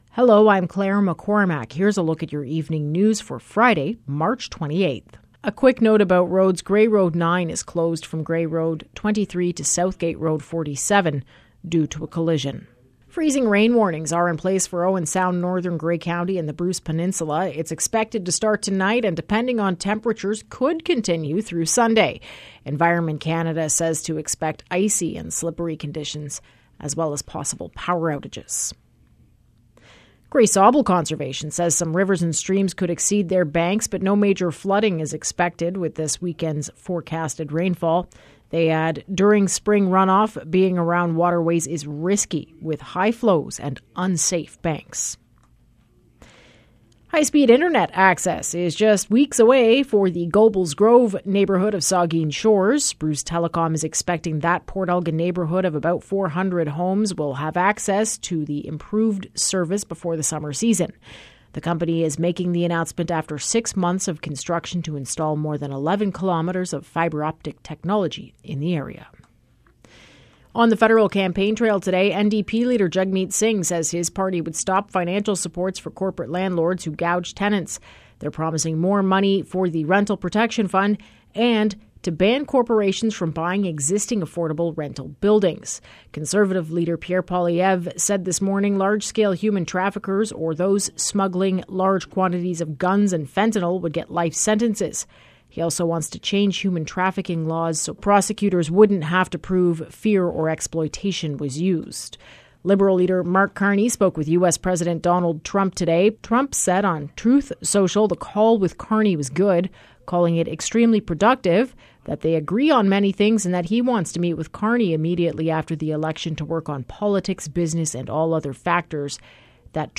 Evening News – Friday March 28